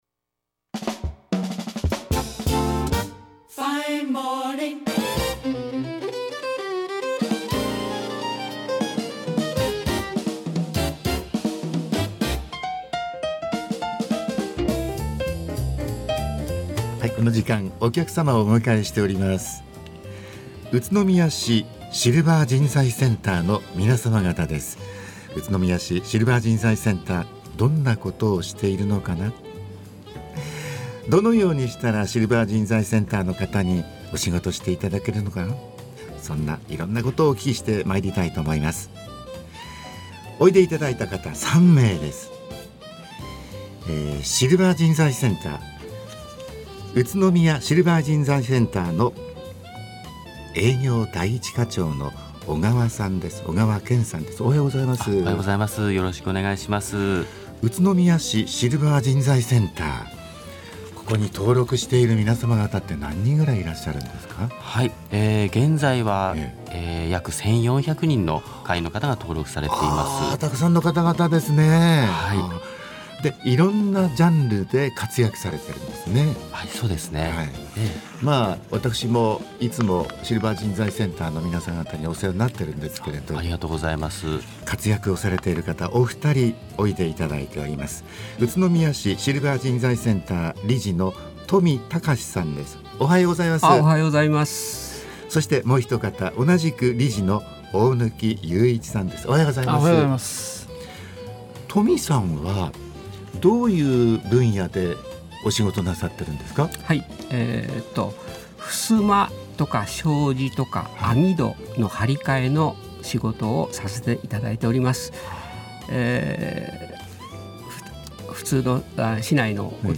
栃木放送ラジオに生出演しました!!
宇都宮市SCで活躍中の会員さんが栃木放送ラジオ番組に生出演し、シルバー人材センターの現状や、シルバーの仕事を通して体験したエピソードを生の声で楽しくお届けしてくれました。 また、各センターで力を入れている事業や今後の課題についても紹介し、シルバー人材センターをより知って頂く良い機会となったはずです!!